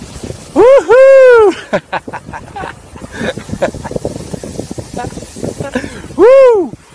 Soundboard
Wohoo.wav